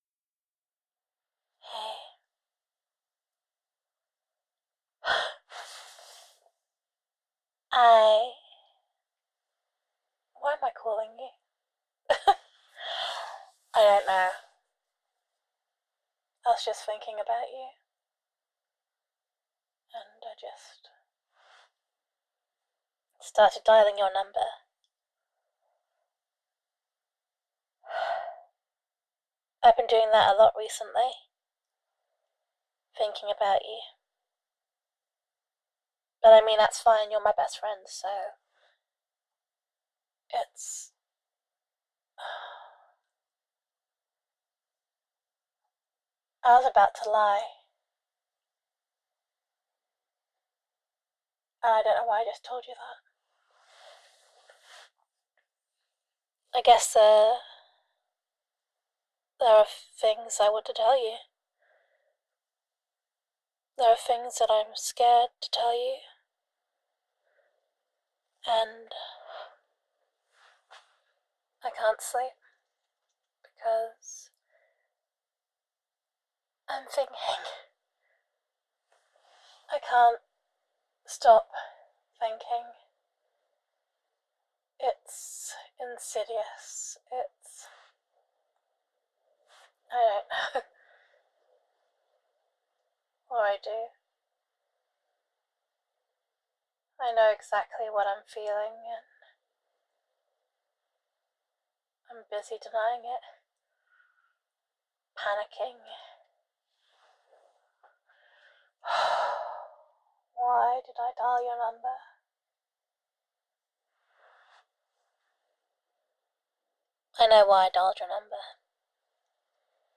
[F4A] Why Am I Calling You? [Thinking About You][I Was About to Lie][I Can’t Sleep][Wanting to Tell You Something][Best Friend Roleplay][Telephone EQ][Gender Neutral][Best Friend Voicemail]